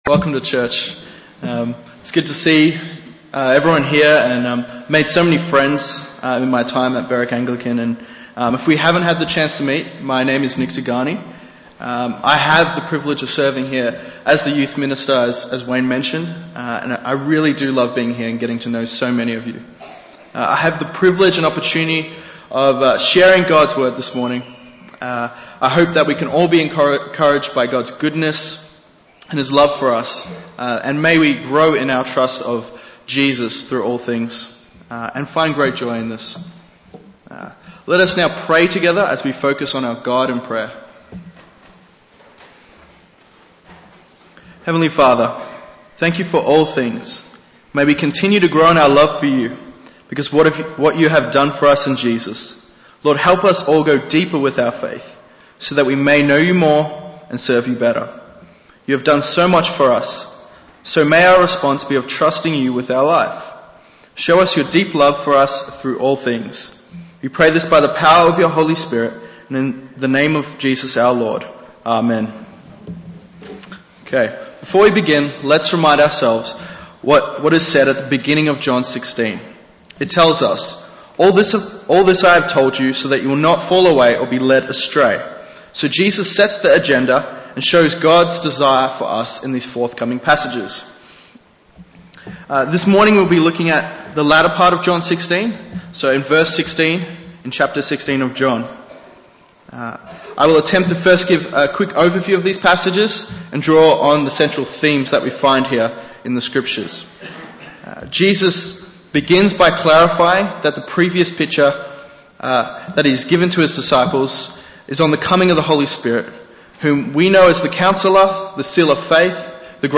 Bible Text: John 16:17-33 | Preacher